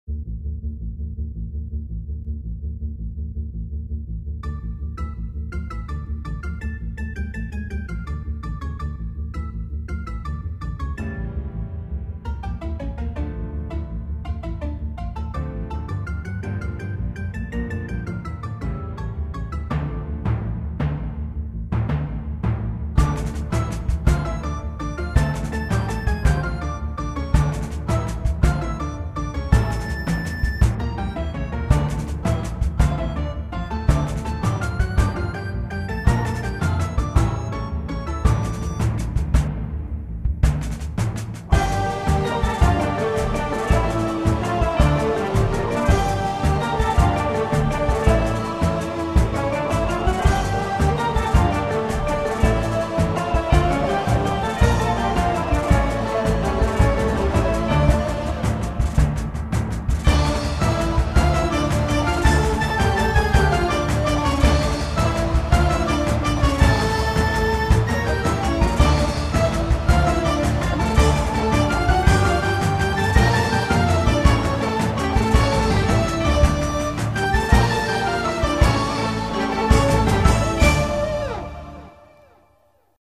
oompah and belly dance
...is a short orchestral piece composed in May during the coronavirus pandemic of 2020